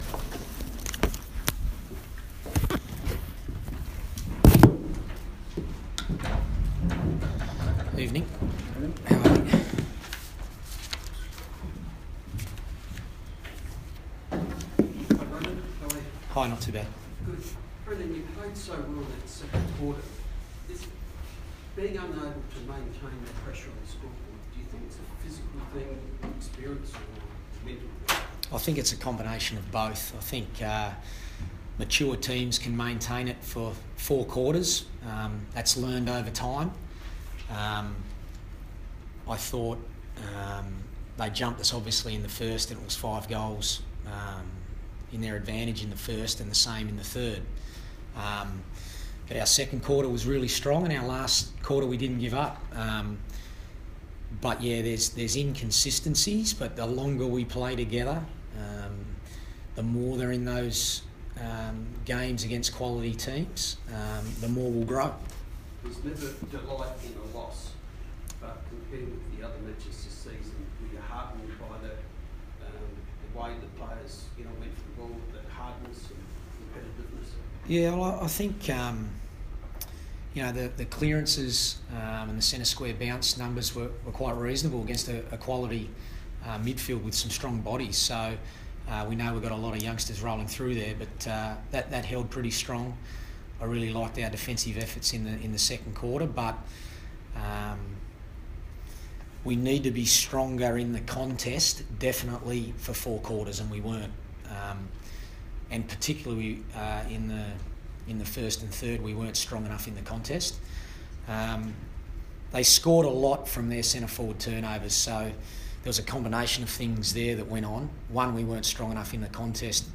Carlton coach Brendon Bolton speaks to the media after the Blues' 55-point loss to Adelaide at Adelaide Oval.